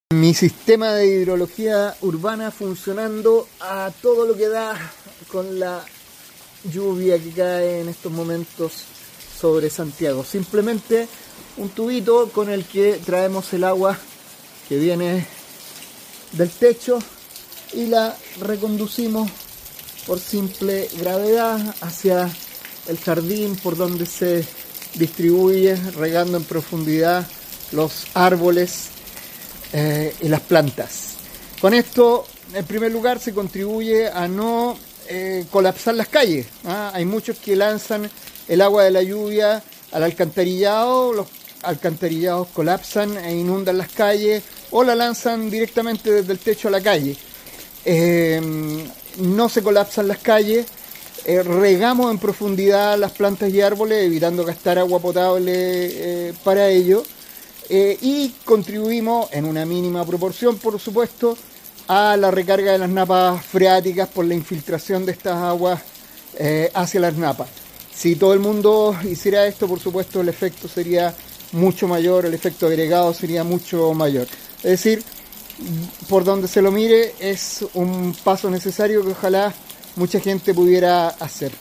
Mi sistema de hidrología urbana funcionando a todo lo que da para cosechar esta bendita lluvia que cae sobre Santiago.